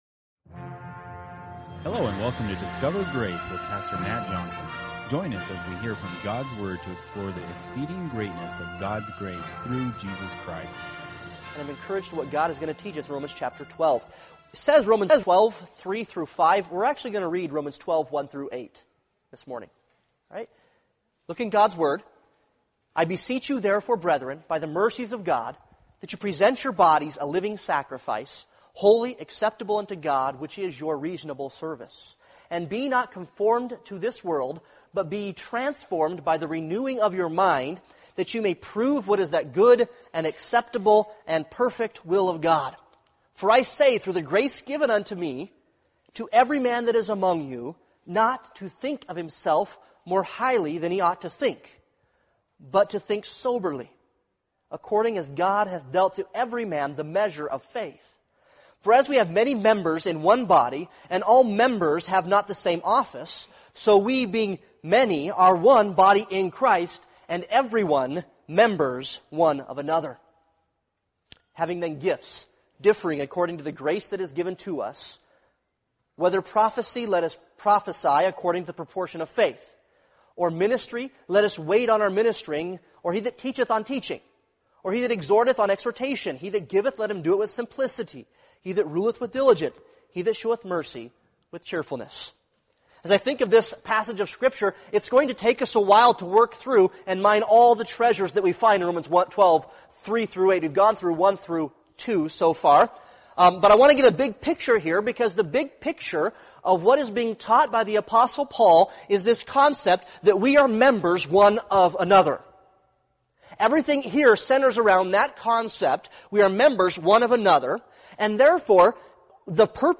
Romans 12:3-5 Service Type: Sunday Morning Worship « Knowing God